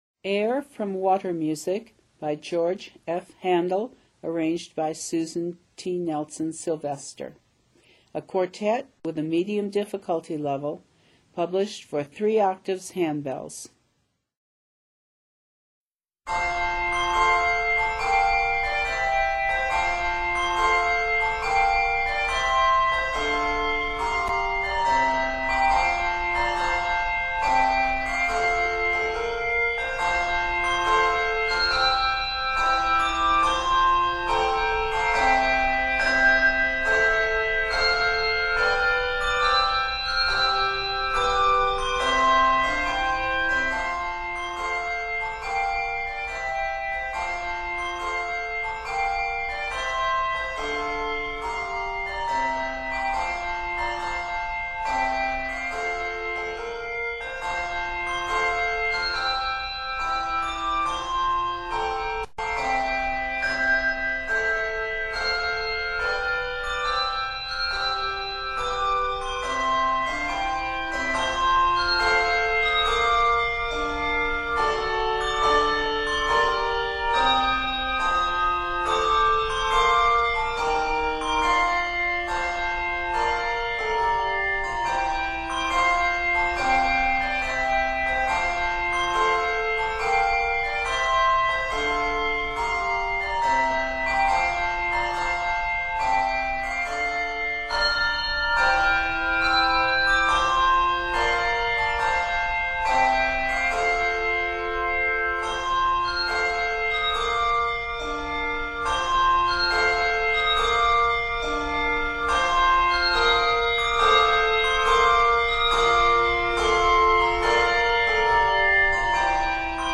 Arranged in F Major, it is 28 measures.
Quartet
Classical Music